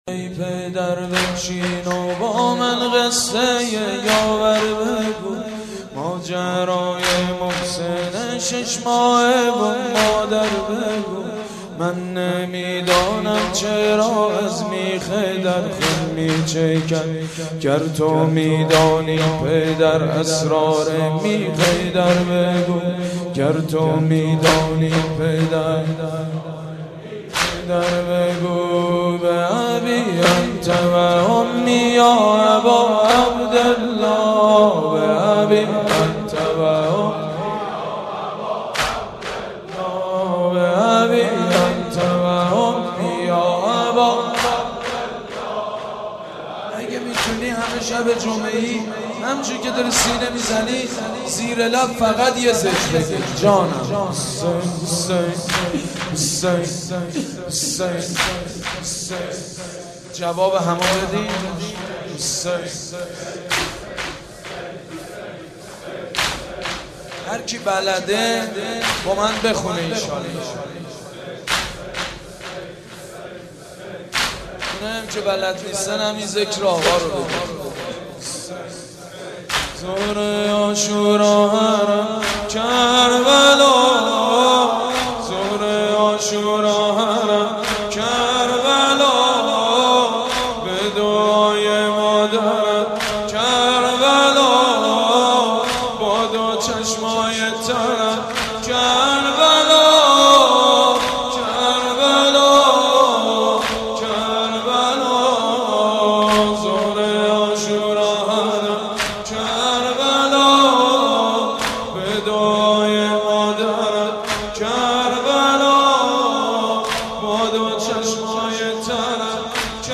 مداحی و نوحه
سینه زنی، شهادت حضرت فاطمه زهرا(س